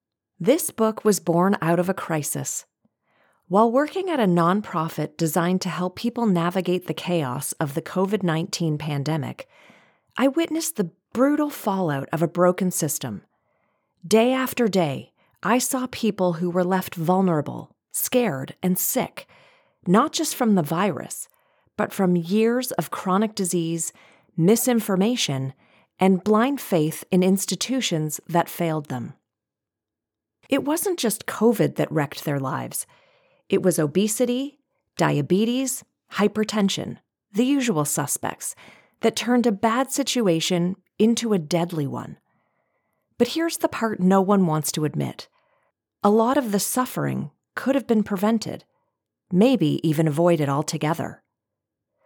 Nonfiction Audiobook
🎙 Broadcast-quality audio
(RODE NT1 Signature Mic + Fully Treated Studio)
Middle Aged Female